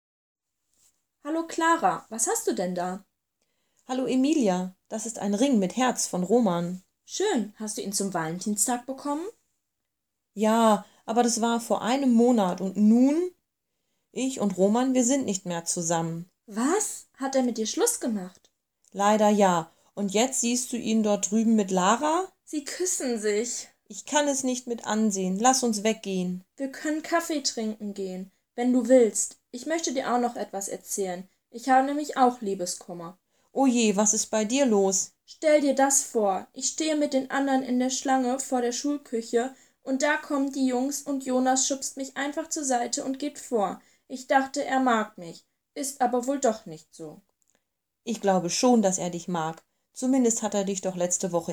Str. 16 - AUDIO - Dialogy
dialogu Ach, ta láska! z rubriky  "Dialogy" na str. 16.